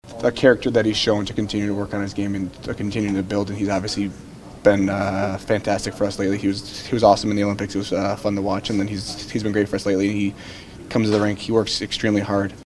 Rust is impressed with how hard Arturs Silovs works at his game.